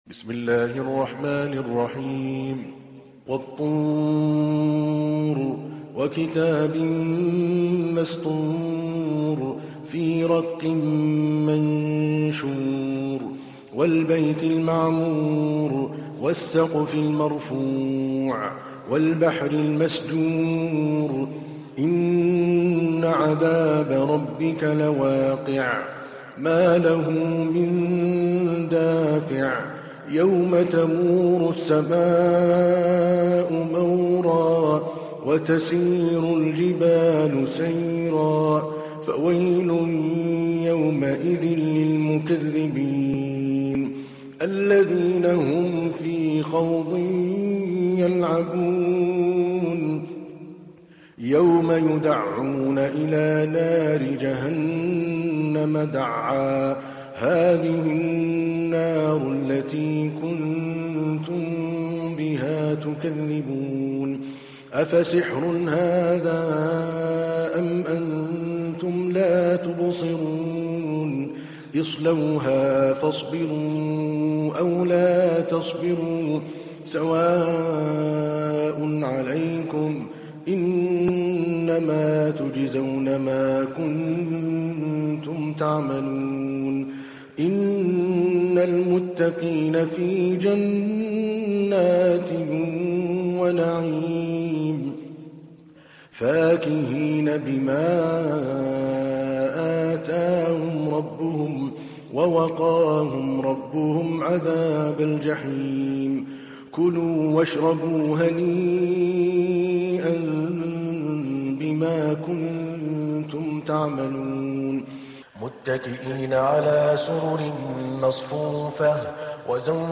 تحميل : 52. سورة الطور / القارئ عادل الكلباني / القرآن الكريم / موقع يا حسين